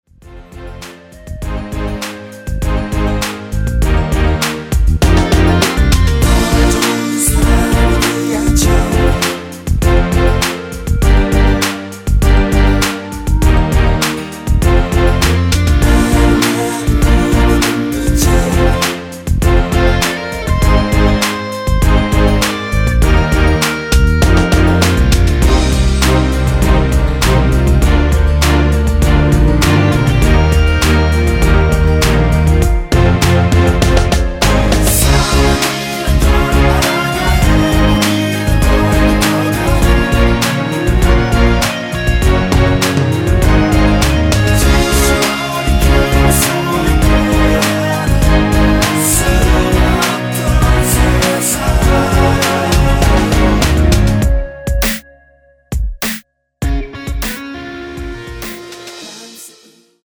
원키에서(-1)내린 멜로디와 코러스 포함된 MR입니다.(미리듣기 참조)
Bb
앞부분30초, 뒷부분30초씩 편집해서 올려 드리고 있습니다.
중간에 음이 끈어지고 다시 나오는 이유는